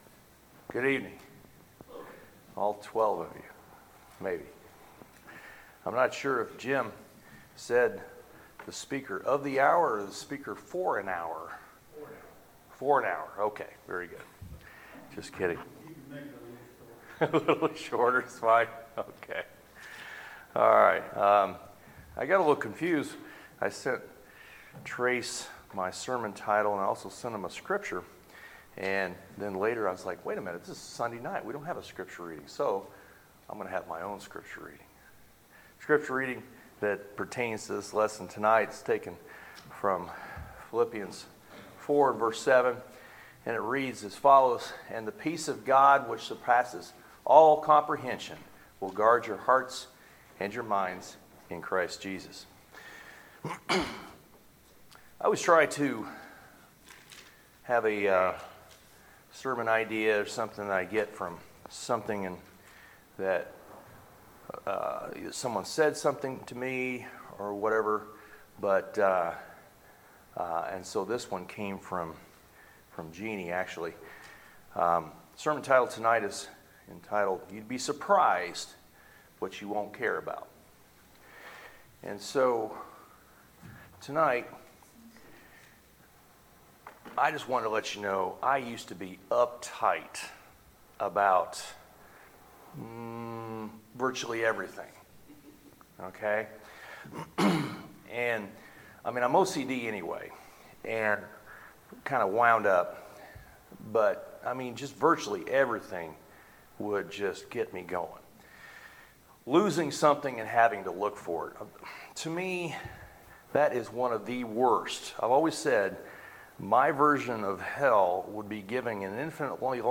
Sermons, November 3, 2019